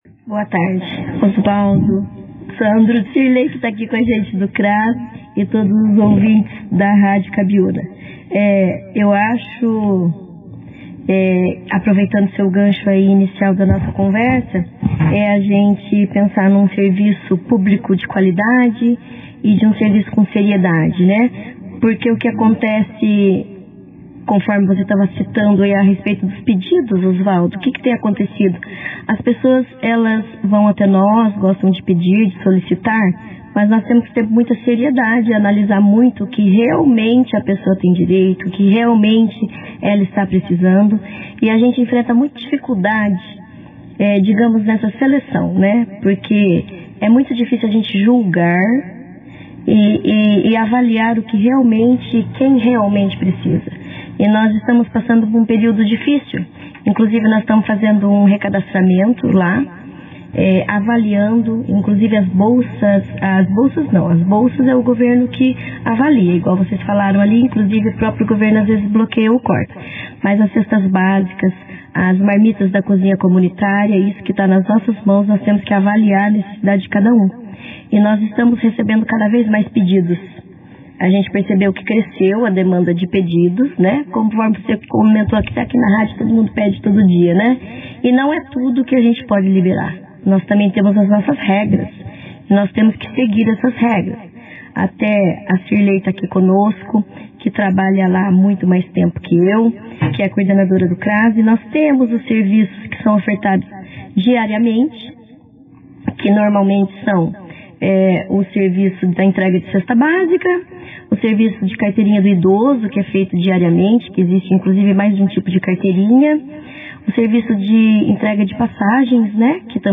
Durante a entrevista, as convidadas falaram sobre os trabalhos que serão desenvolvidos junto à comunidade ao longo de 2026, com destaque para a ampliação de cursos, oficinas e ações sociais voltadas às famílias em situação de vulnerabilidade. Também foram abordados outros assuntos ligados à área social do município, reforçando o papel do CRAS como espaço de acolhimento, orientação e fortalecimento de vínculos comunitários.